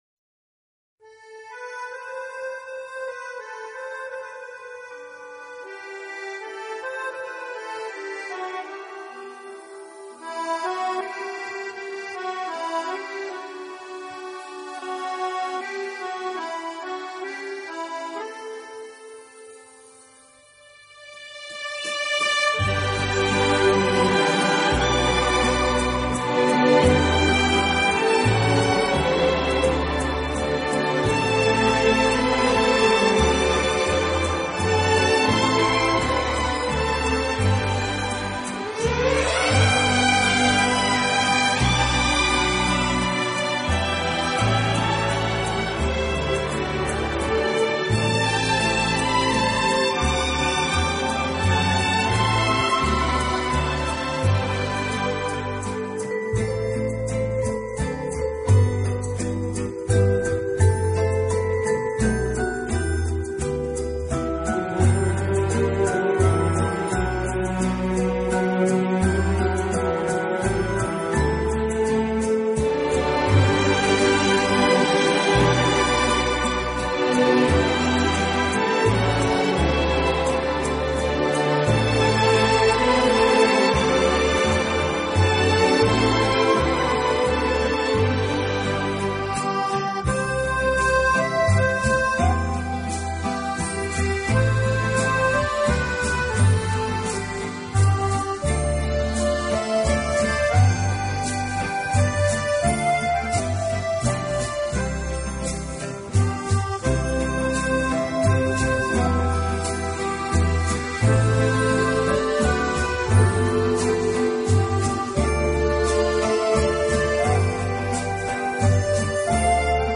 有的音乐改编自古典音乐经典片段、好莱坞浪漫巨片和流行金榜名曲。
十二集超过二百首流行音乐元素与世界各地风情韵味完美结合的音乐，